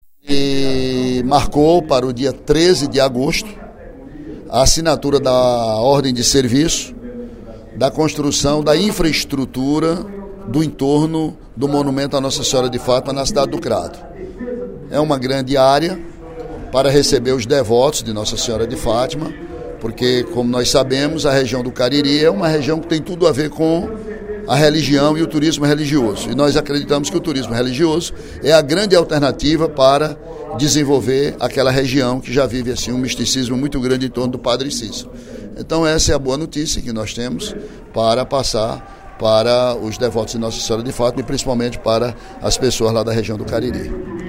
O deputado Ely Aguiar (PSDC) anunciou, no primeiro expediente da sessão plenária desta sexta-feira (15/07), a assinatura da Ordem de Serviço, no dia 13 de agosto, pelo governador Camilo Santana, para construção da infraestrutura do entorno do monumento de Nossa Senhora de Fátima no município do Crato.